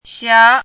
chinese-voice - 汉字语音库
xia2.mp3